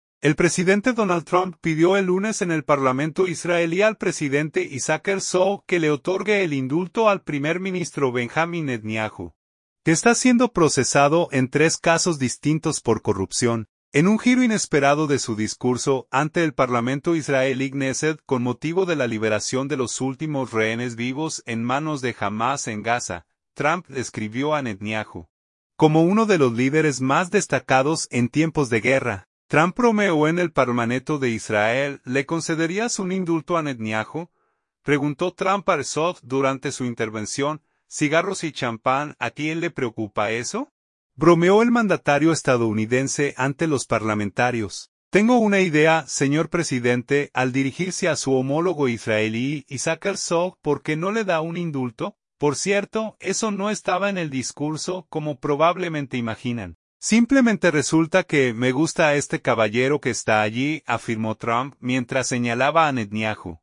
En un giro inesperado de su discurso, ante el Parlamento israelí (Knéset), con motivo de la liberación de los últimos rehenes vivos en manos de Hamás en Gaza, Trump describió a Netanyahu, como “uno de los líderes más destacados” en tiempos de guerra.
Trump bromeó en el Parlmaneto de Israel: